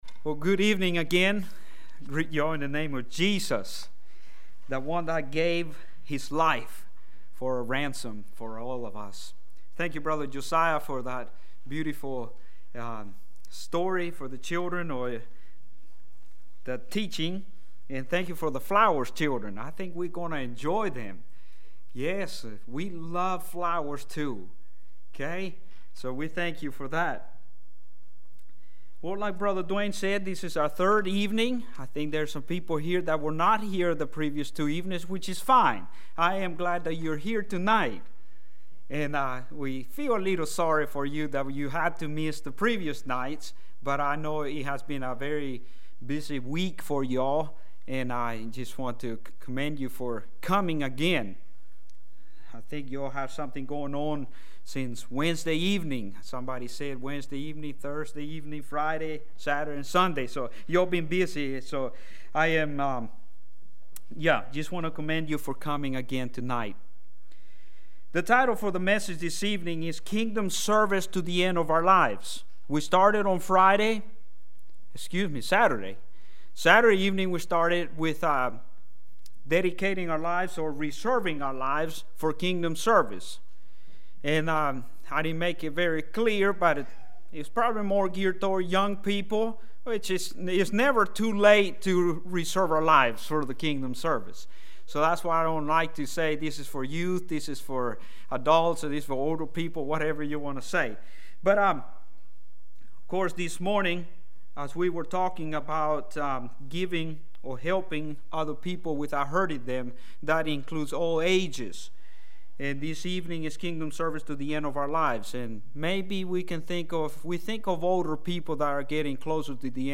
Sermons
Revivals 2024